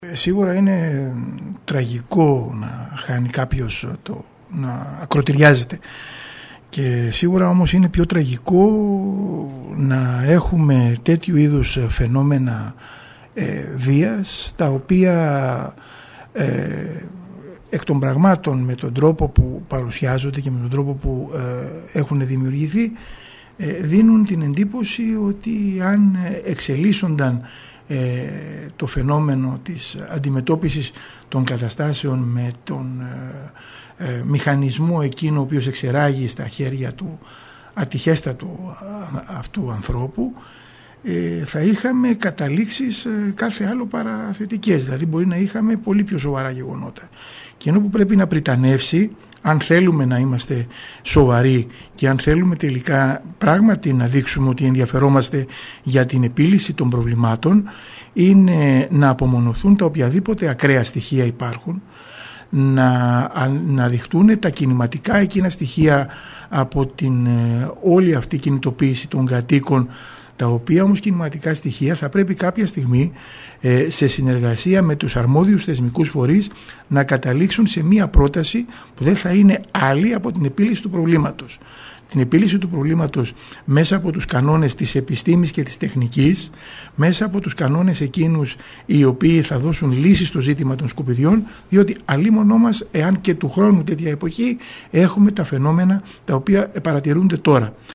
Στις εξελίξεις των κινητοποιήσεων στη Λευκίμμη αναφέρθηκε  ο Περιφερειακός Σύμβουλος Τάσος Σαλβάνος, επικεφαλής της παράταξης «Αριστερή Κίνηση Ιονίου» μιλώντας στην ΕΡΤ Κέρκυρας. ο κ. Σαλβάνος  τόνισε ότι μέσα από την τραγικότητα του ακροτηριασμού ενός συμπολίτη μας, πρέπει να δούμε την επικίνδυνη κατάληξη που θα μπορούσε να έχει ένα περιστατικό βίας στην εξέλιξή του για τη ζωή συνανθρώπων μας.